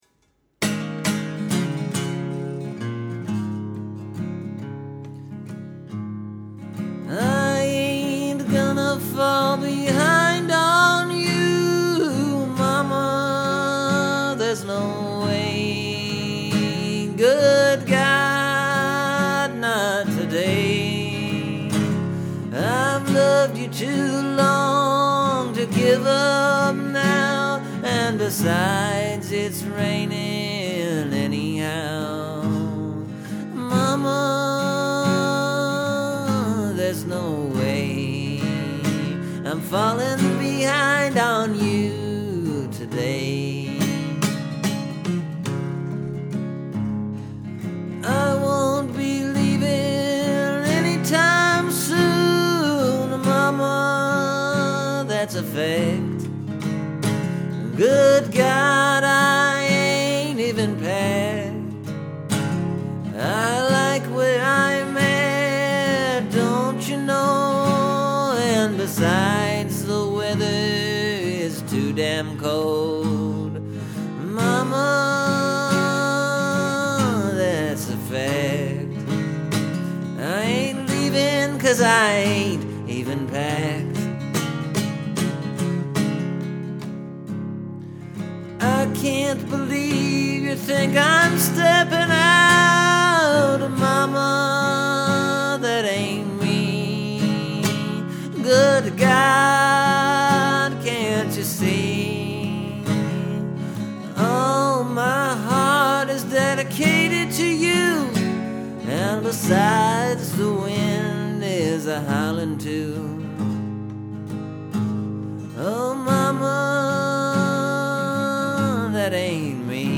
And, I used an old trick of mine (that I haven’t used for a while): Making a song sound new by changing it into 3/4 time.